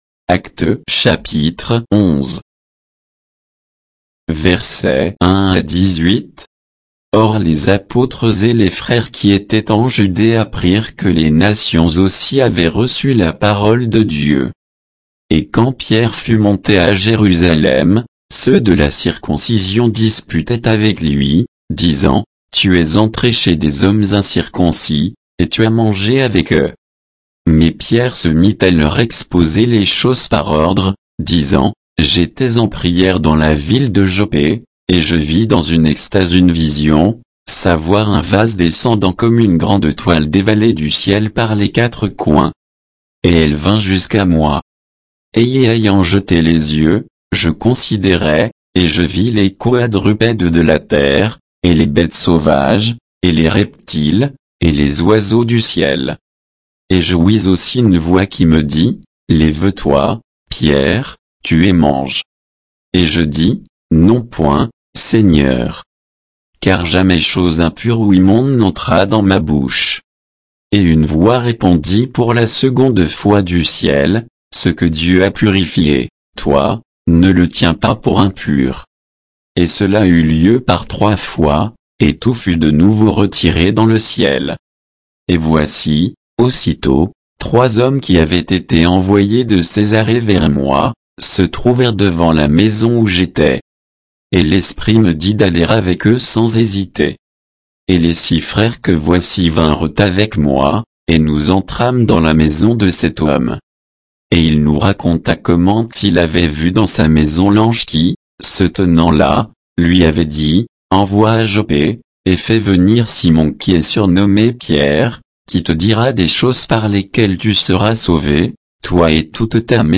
Bible_Actes_11_(sans_notes,_avec_indications_de_versets).mp3